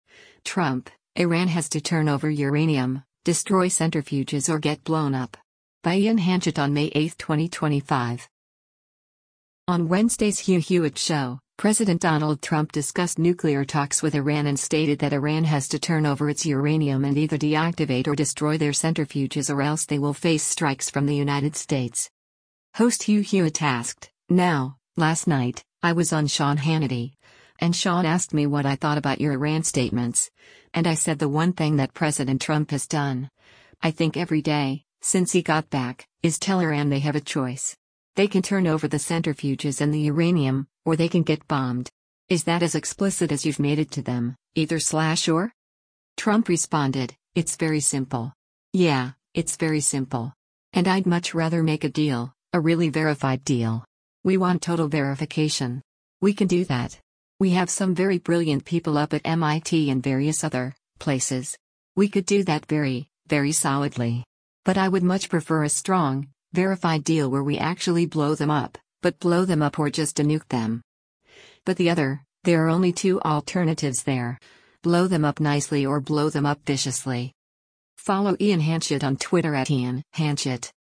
On Wednesday’s “Hugh Hewitt Show,” President Donald Trump discussed nuclear talks with Iran and stated that Iran has to turn over its uranium and either deactivate or destroy their centrifuges or else they will face strikes from the United States.